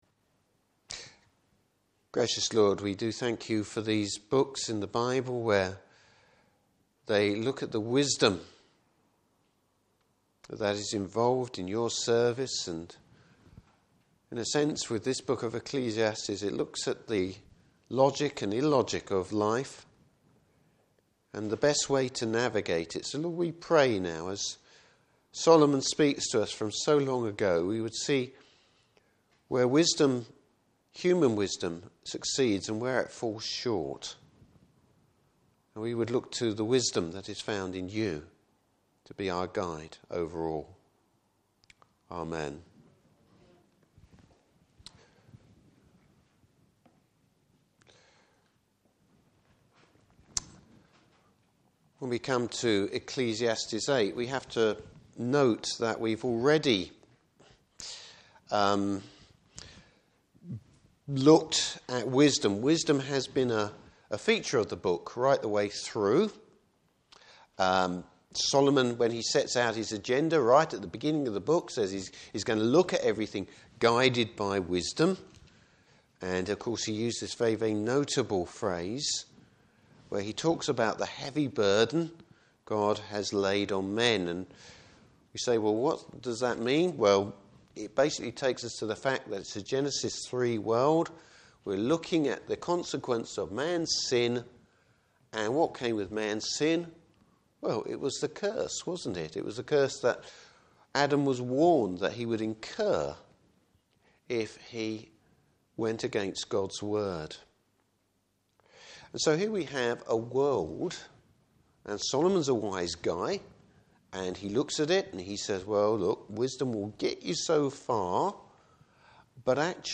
Service Type: Morning Service Bible Text: Ecclesiastes 8.